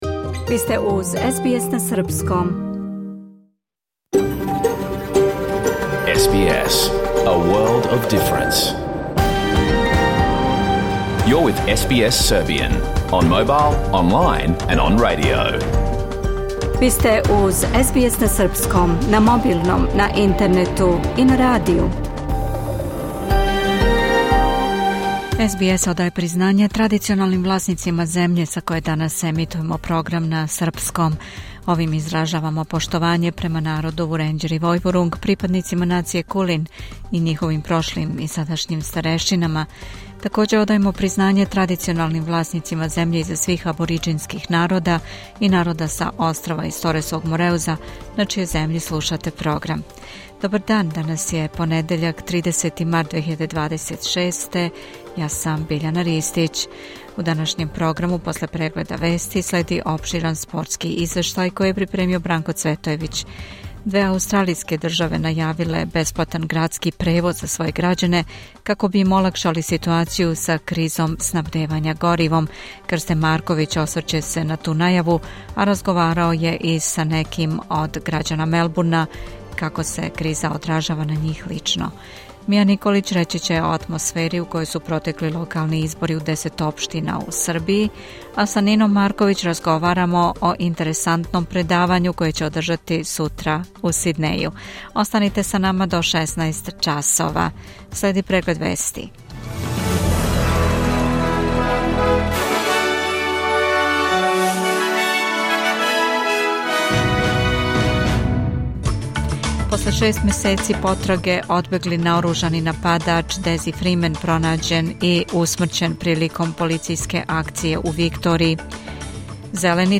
Програм емитован уживо 26. марта 2026. године